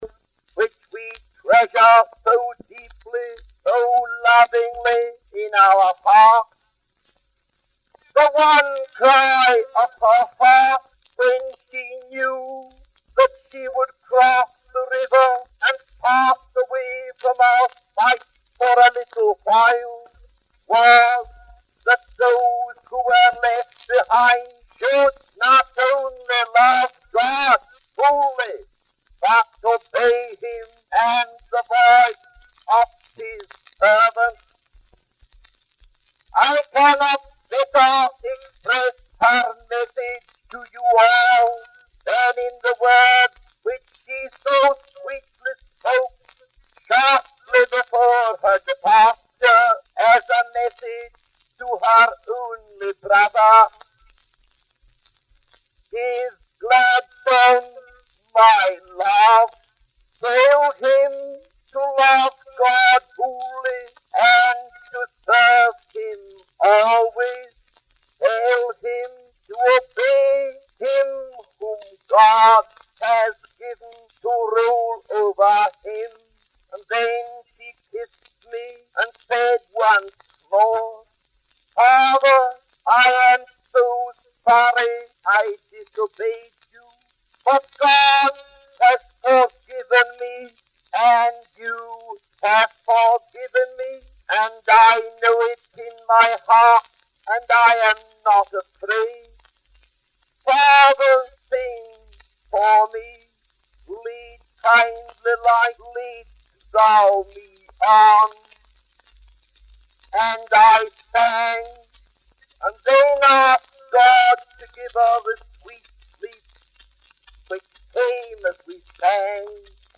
In this sermon, the speaker shares a heartfelt message to the audience. The speaker emphasizes the importance of loving and serving God wholeheartedly.